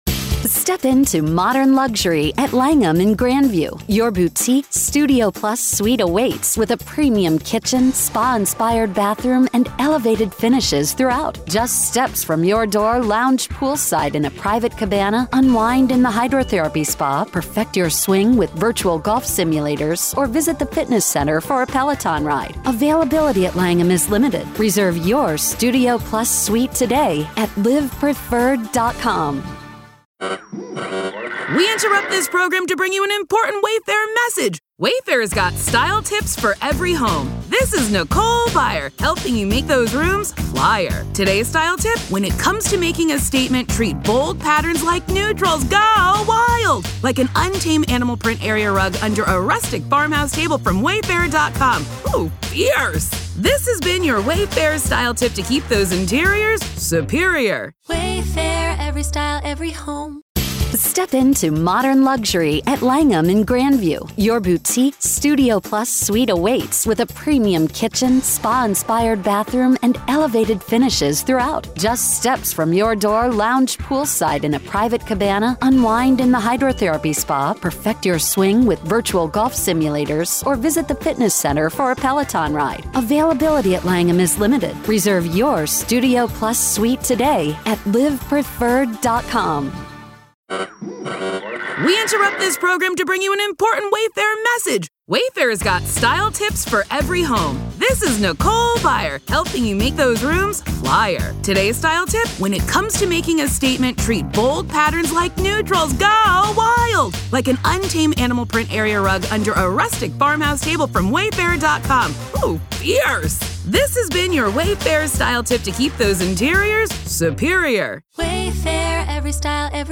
Dr. Katherine Ramsland Interview Behind The Mind Of BTK Part 4